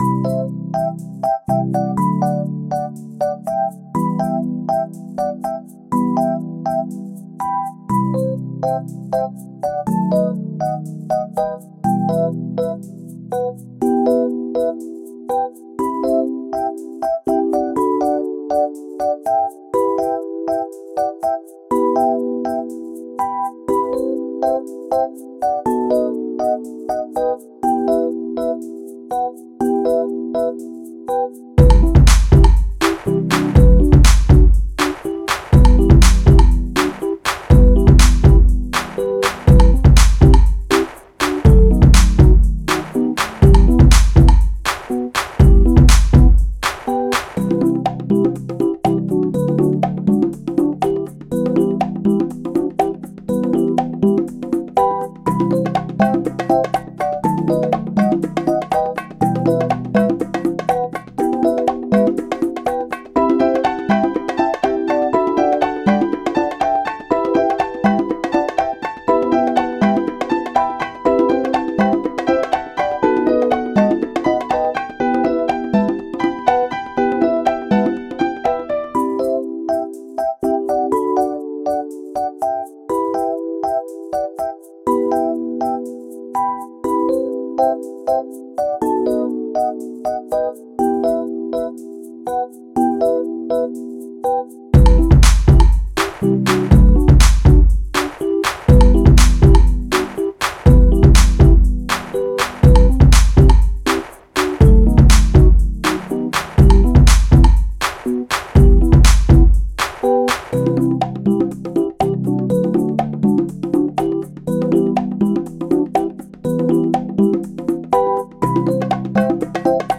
2년전쯤의 말랑한곡 | 리드머 - 대한민국 힙합/알앤비 미디어